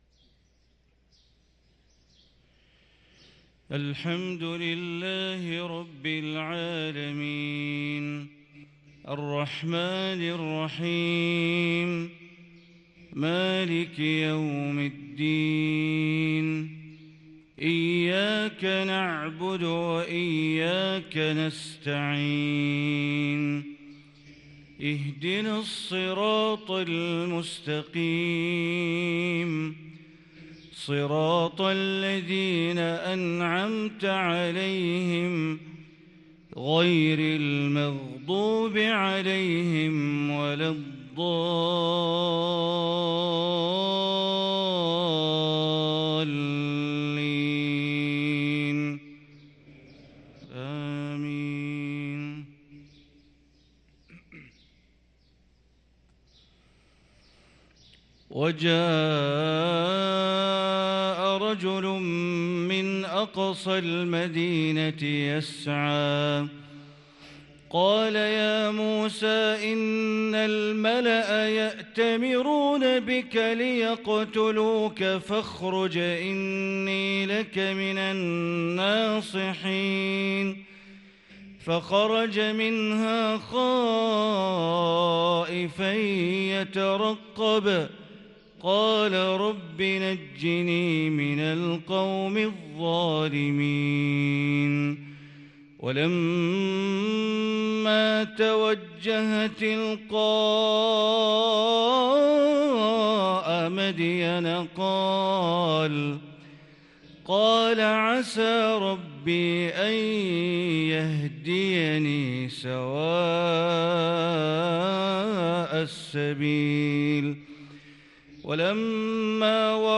صلاة الفجر للقارئ بندر بليلة 9 جمادي الآخر 1444 هـ
تِلَاوَات الْحَرَمَيْن .